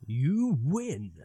Voices / Male / You Win.wav
You Win.wav